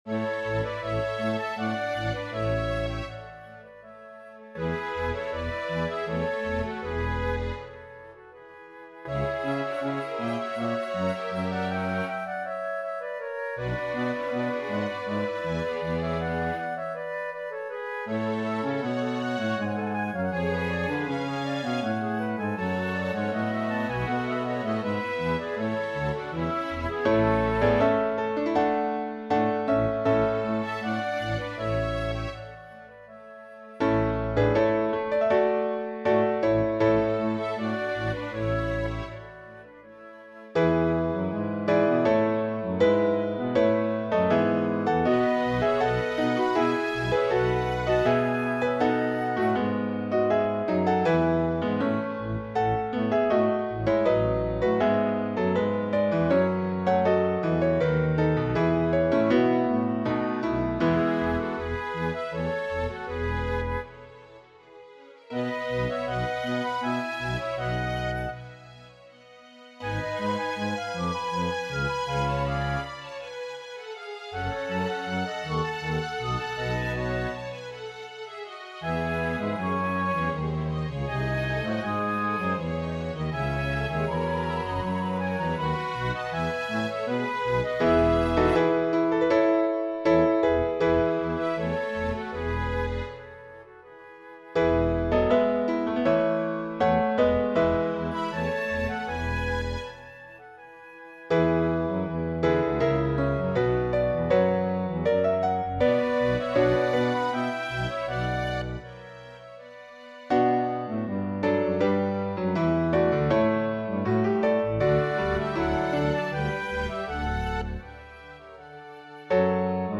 MP3 Dateien von allen Chorstücken nach Register
BWV234 -1a Kyrie_SATB.mp3